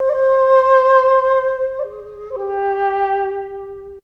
ZG FLUTE 7.wav